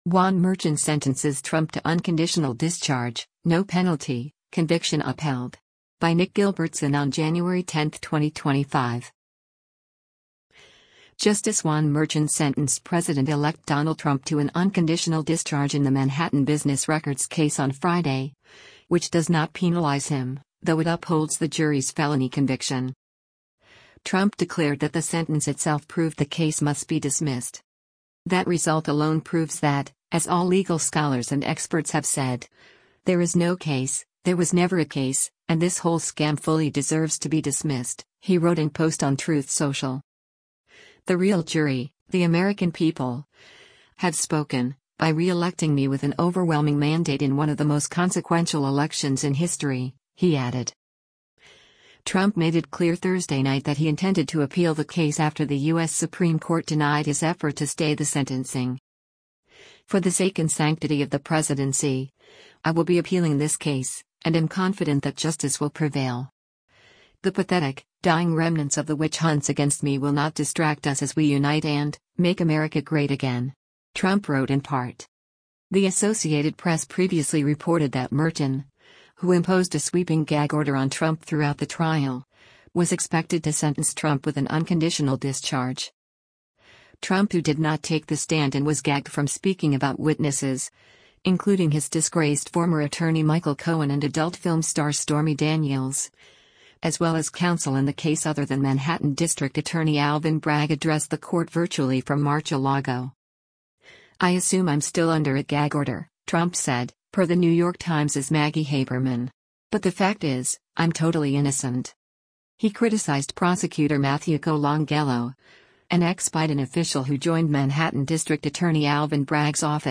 US President-elect Donald Trump appears remotely for a sentencing hearing in front of New